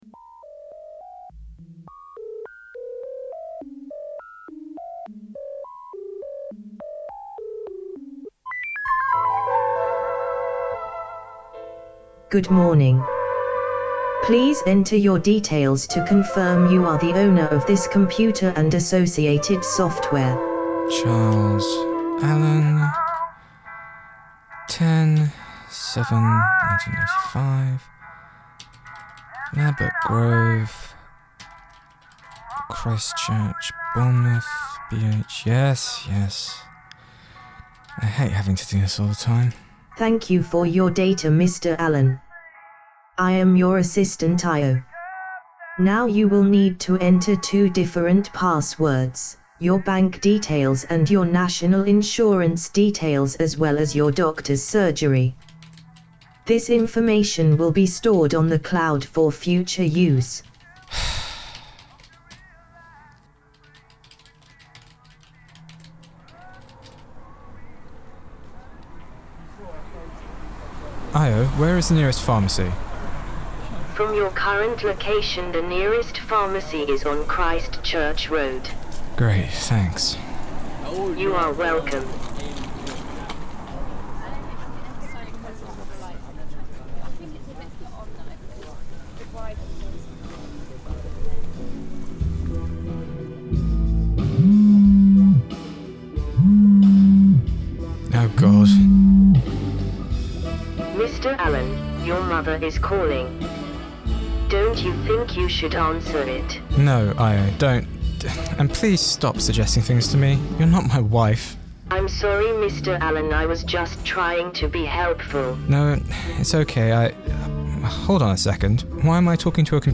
Hello and welcome to our first bit of home grown drama – IO (Pre Alpha).
This programme is best listened to with headphones.
IO – Microsoft Hazel Voice